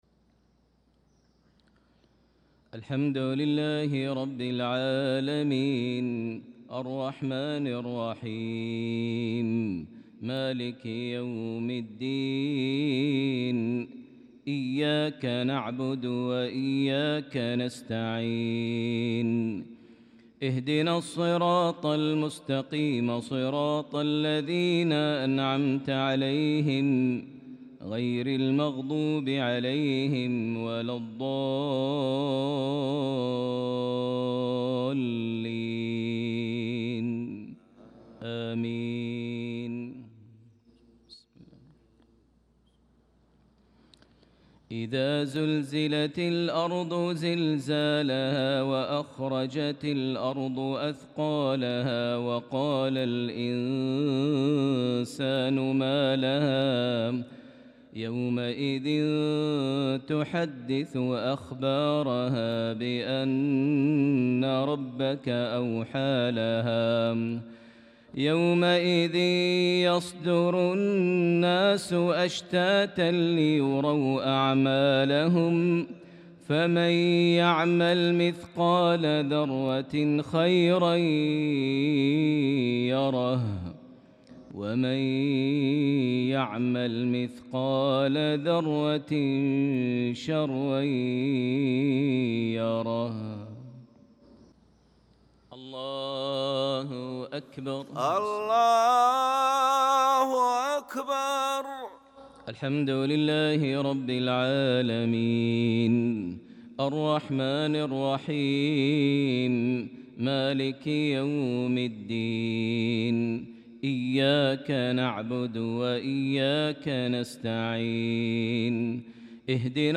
صلاة المغرب للقارئ ماهر المعيقلي 20 شوال 1445 هـ
تِلَاوَات الْحَرَمَيْن .